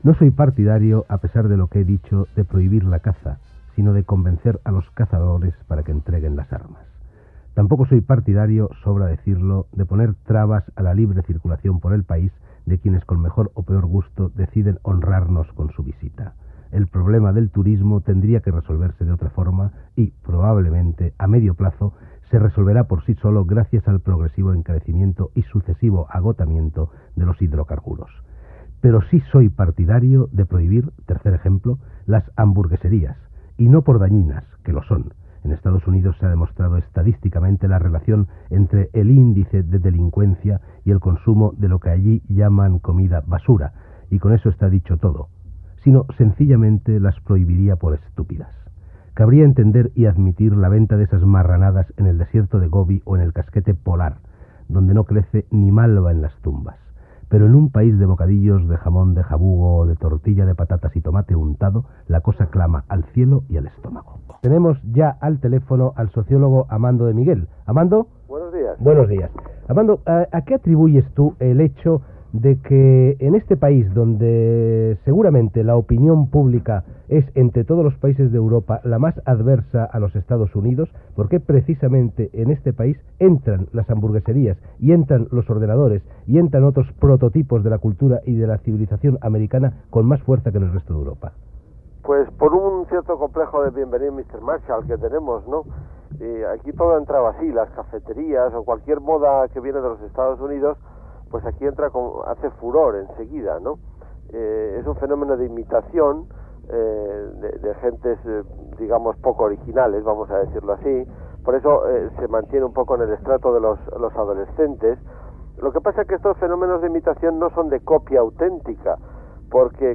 Opinió sobre les hamburgueseries i intervenció del socòleg Amando de Miguel
Entreteniment